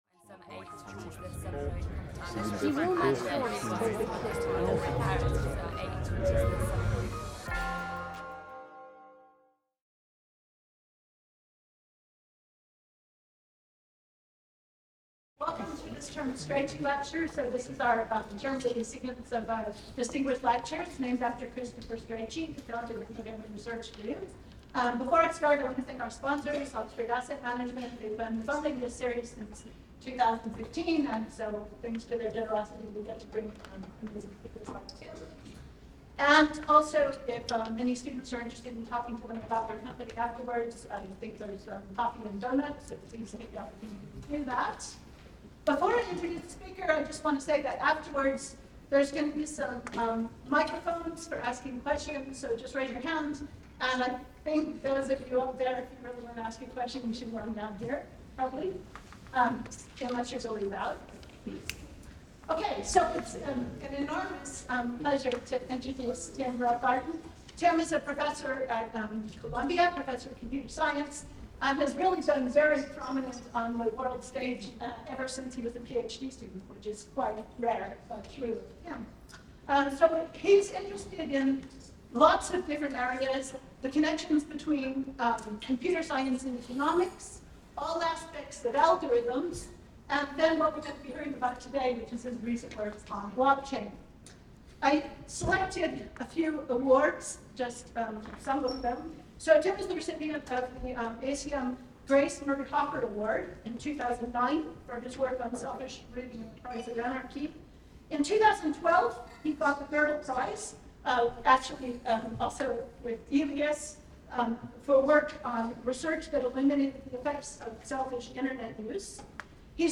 Strachey Lecture: The Computer in the Sky